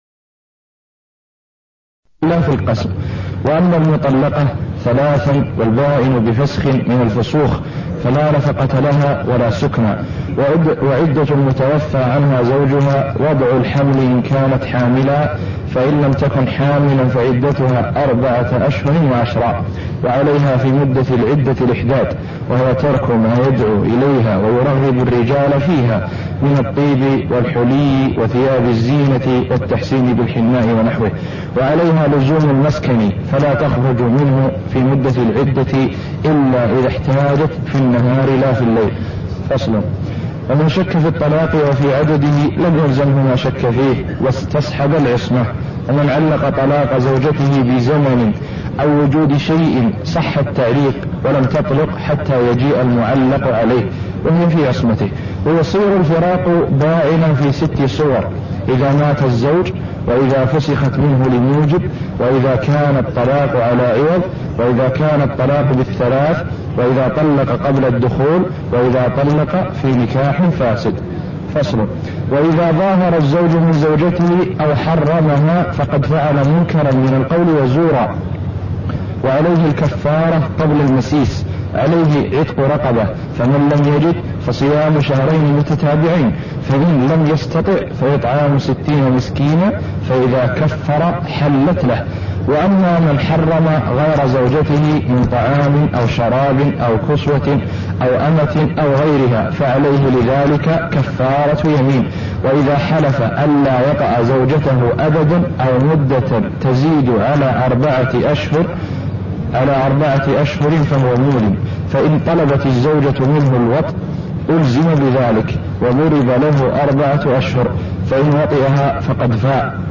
تاريخ النشر ٢٠ رمضان ١٤٣١ هـ المكان: المسجد النبوي الشيخ: معالي الشيخ د. سعد بن ناصر الشثري معالي الشيخ د. سعد بن ناصر الشثري كتاب الطلاق والعدة (0001)